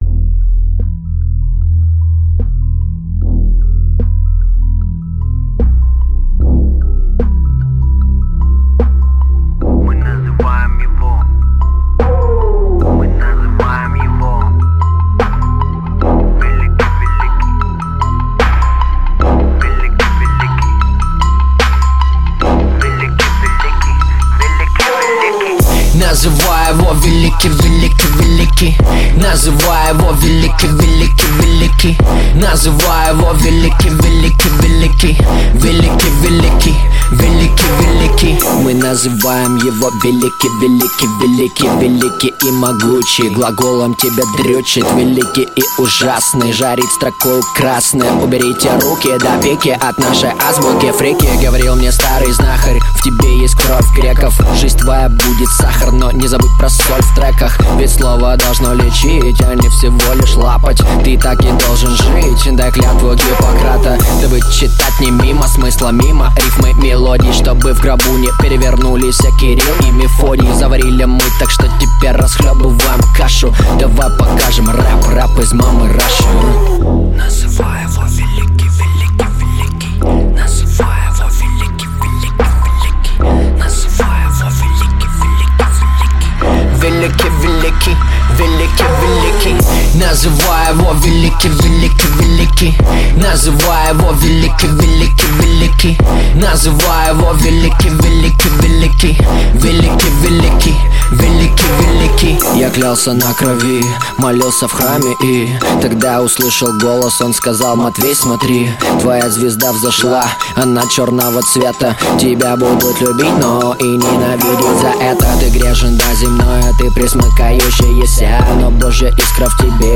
Жанр: Русский рэп / Хип-хоп
• Жанр песни: Русский рэп / Хип-хоп